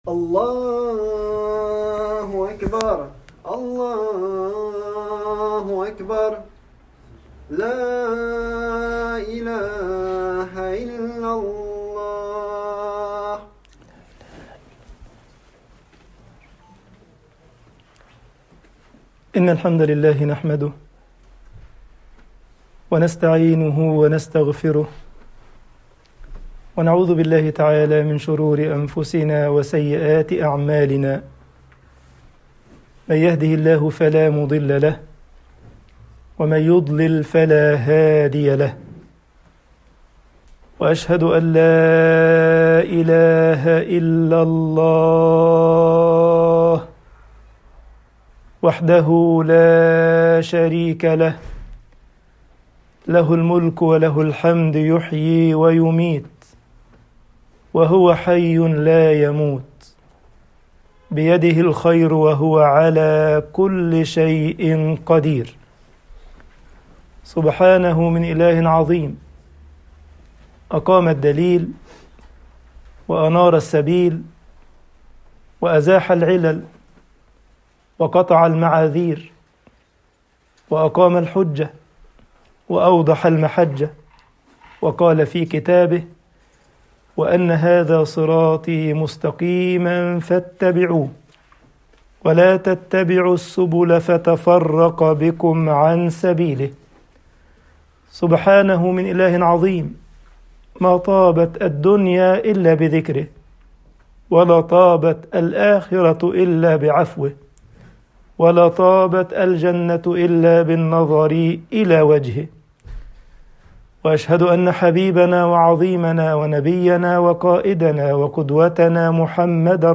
خطب الجمعة والعيد Download التفاصيل حدث بتاريخ